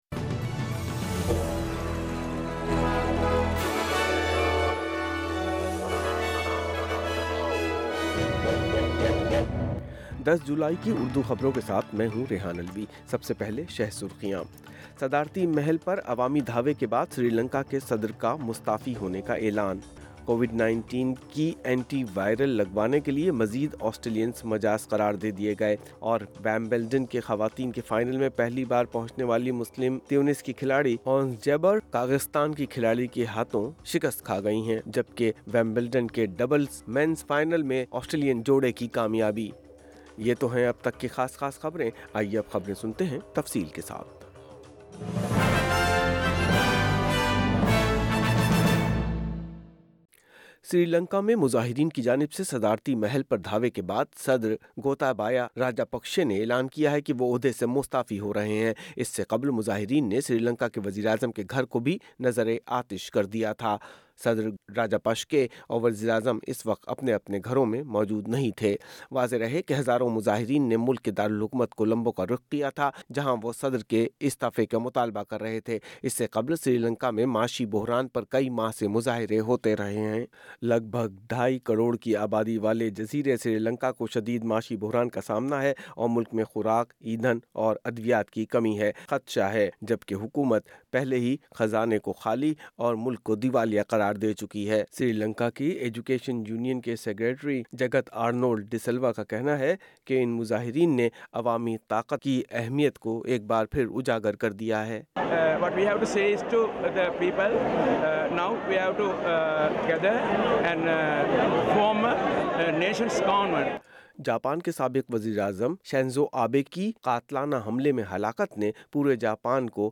Sri Lanka's president and prime minister agree to step down after violent protests-Urdu News Sun 10 Jul 2022